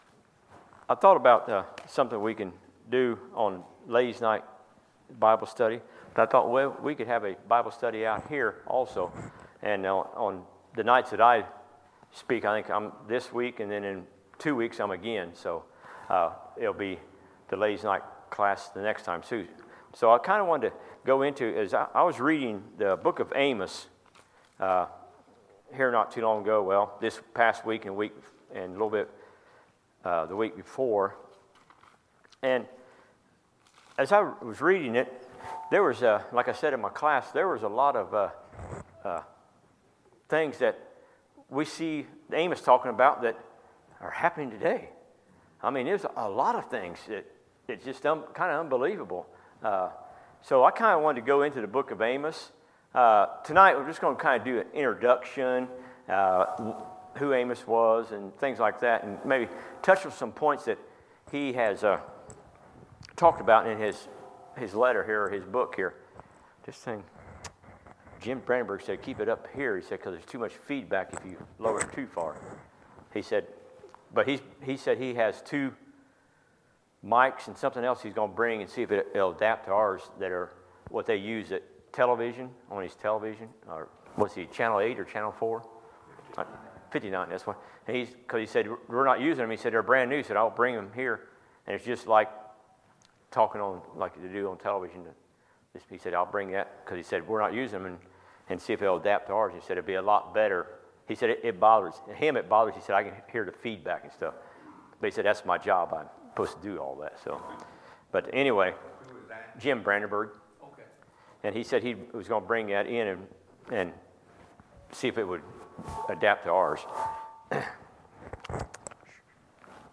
Sermons, February 3, 2019